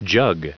Prononciation du mot jug en anglais (fichier audio)
Prononciation du mot : jug